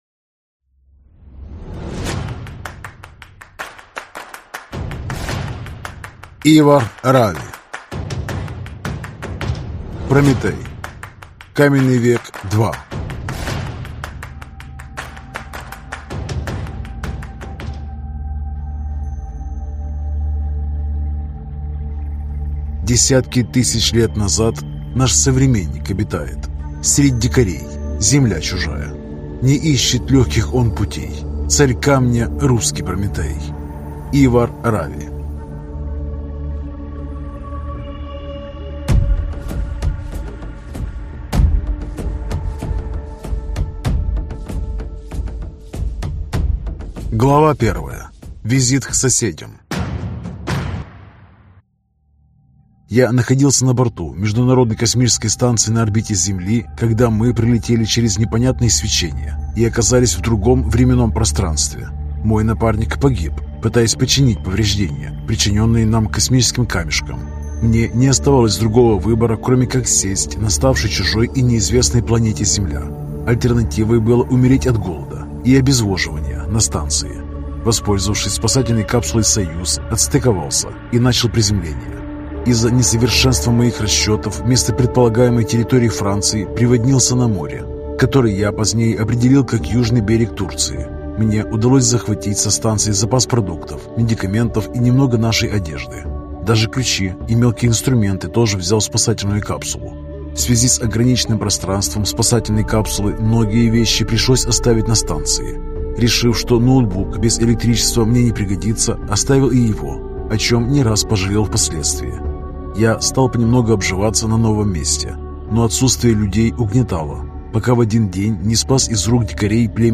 Аудиокнига Каменный век-2 | Библиотека аудиокниг